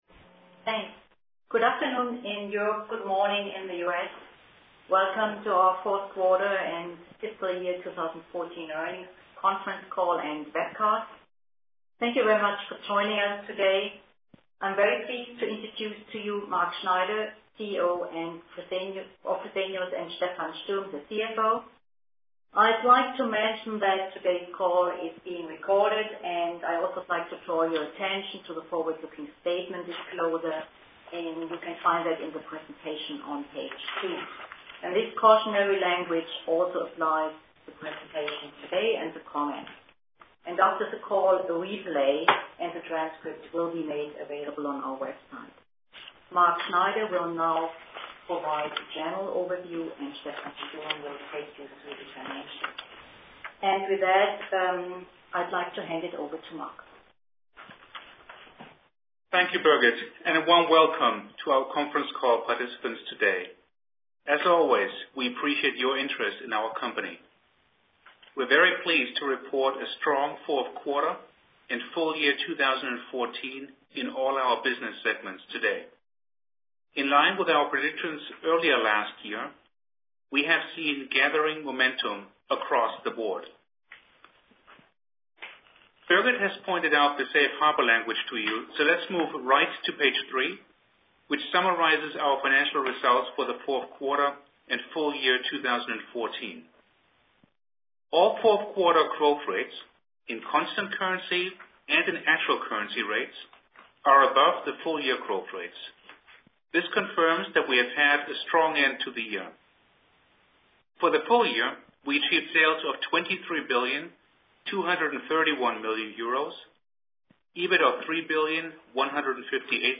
Live-Übertragung im Internet (Die Konferenzsprache ist englisch)